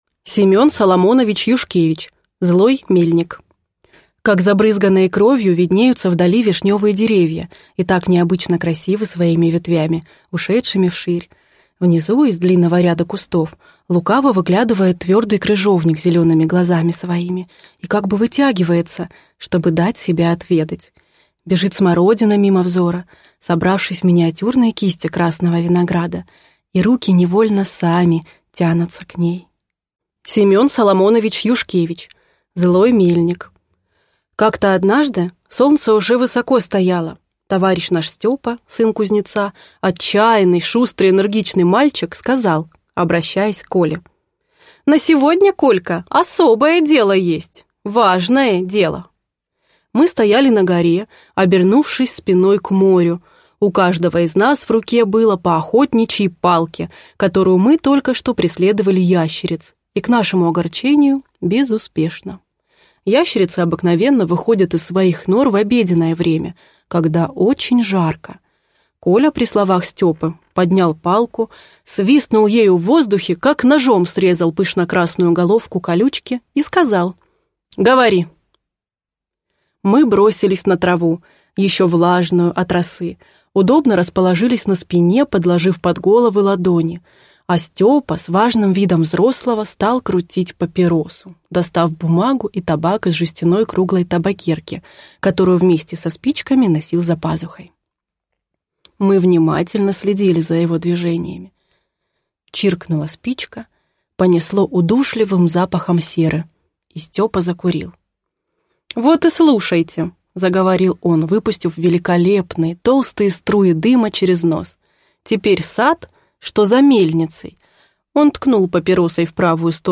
Аудиокнига Злой мельник | Библиотека аудиокниг